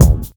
KICKSTACK2-L.wav